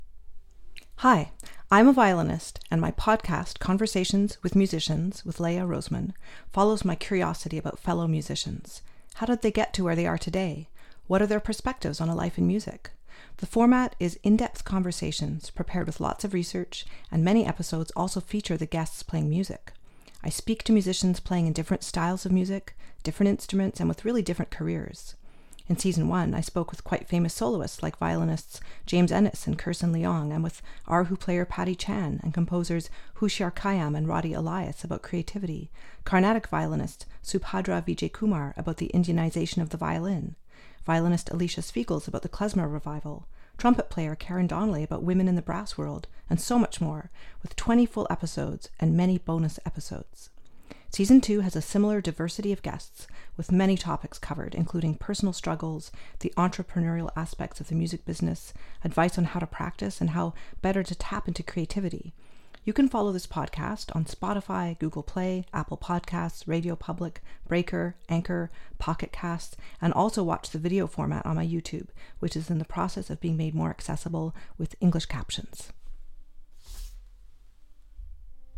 The format is in-depth conversations prepared with lots of research and many episodes also feature the guests playing music.